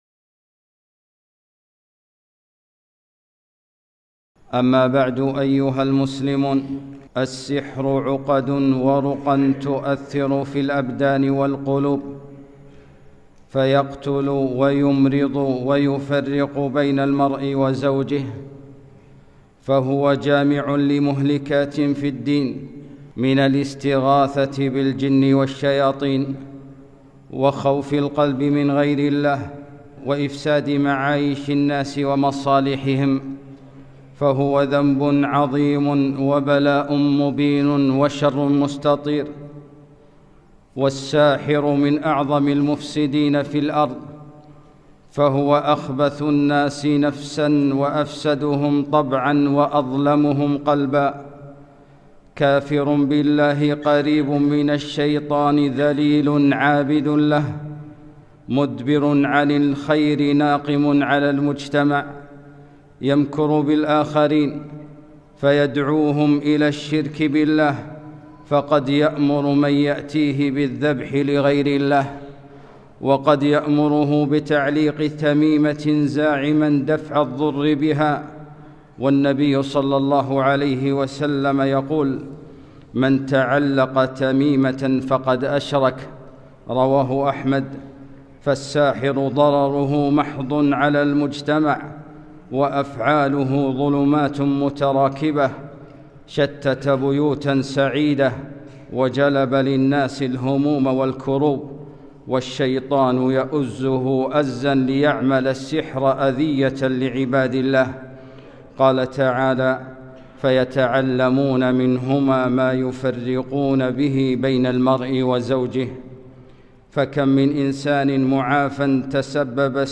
خطبة - البَائِعُ دِينَهُ